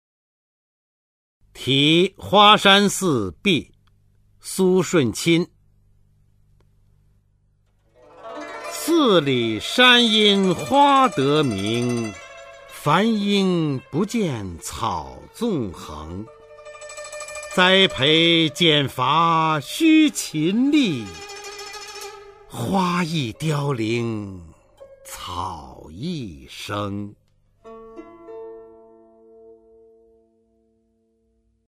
[宋代诗词诵读]苏舜钦-题花山寺壁 宋词朗诵